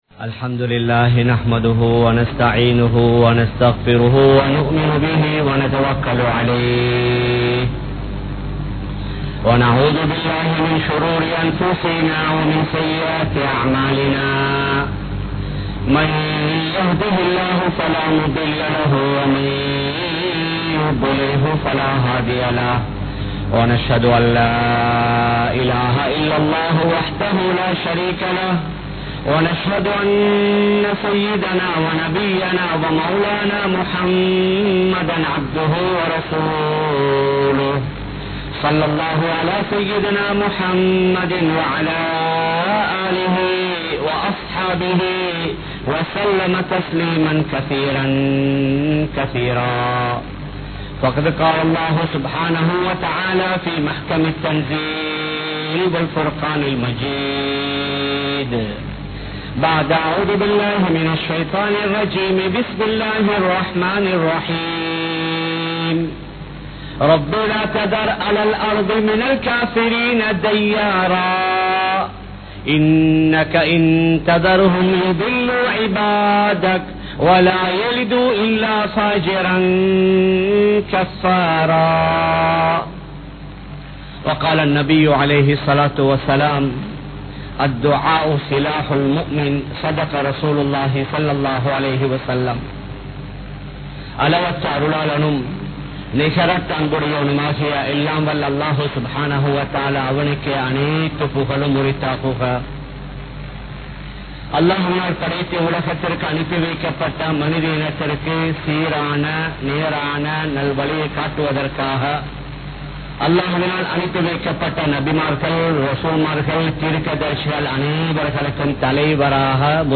Dua vin Sakthi (துஆவின் சக்தி) | Audio Bayans | All Ceylon Muslim Youth Community | Addalaichenai